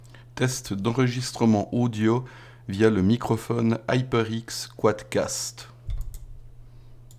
À savoir que ceux-ci ont été enregistré sans ajout d’effets audio.
Microphone HyperX Quadcast :
Test-HyperX-Quadcast.mp3